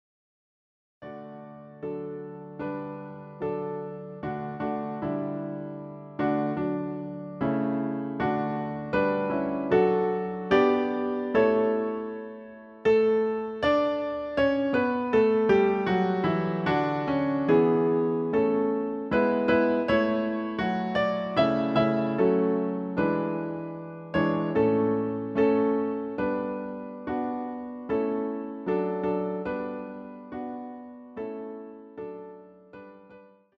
using the stereo sampled sound of a Yamaha Grand Piano.